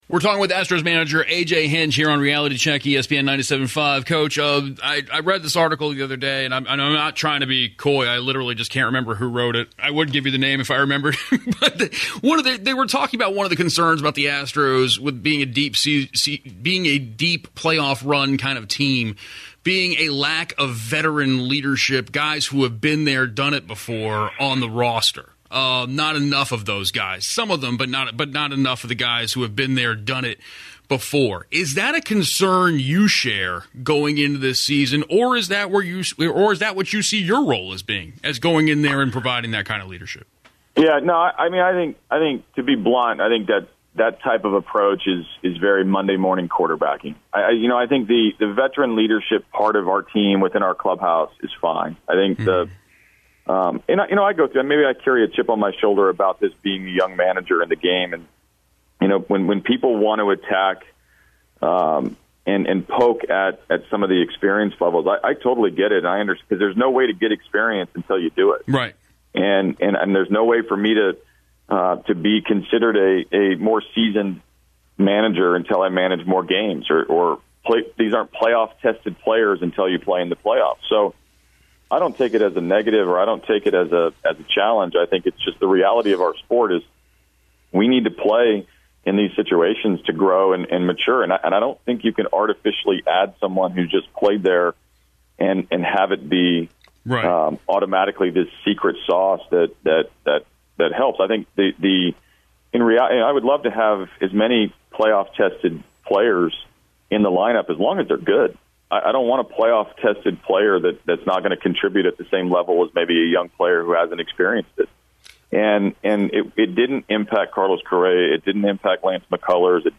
Houston Astros manager A.J. Hinch tells "Reality Check" why lack of experience is not a determining factor when judging the 2016 Houston Astros team.